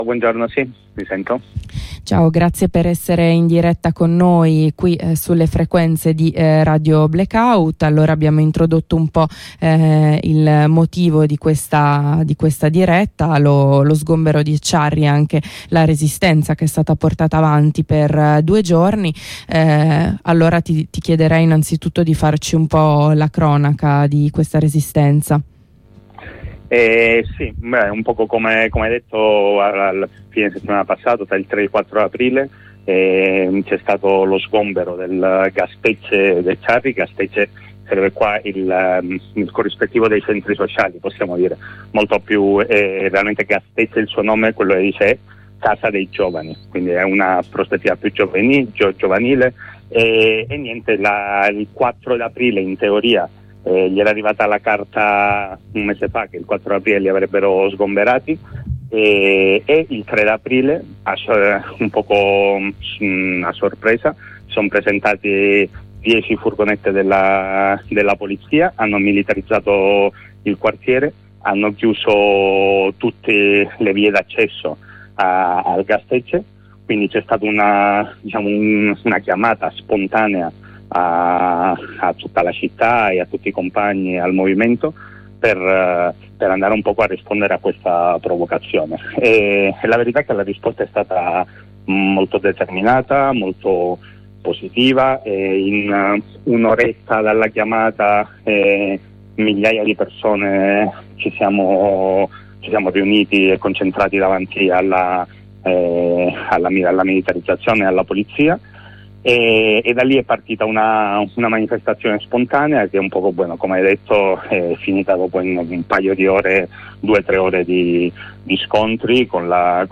Più in generale, qual’è lo stato del movimento popolare in Euskal Herria alla luce delle trasformazioni in senso bellico e reazionario che stanno avvenendo in Europa e nel mondo? Ne parliamo con un compagno di Askapena.